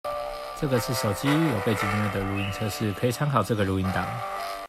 根據我實測結果我的看法是～我只能說抗噪確實不錯各位可以聽看看我錄製的 4 組參考錄音檔，有背景音樂的部分我故意找之前做有破音的音樂來測試
▶ 有背景音樂錄音檔
● 手機收音